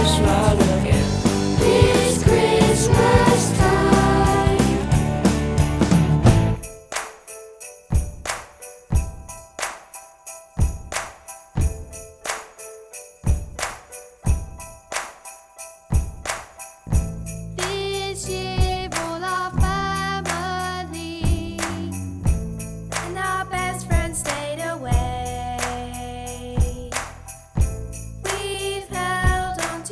a collection of around 20 local children